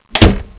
doorclos.mp3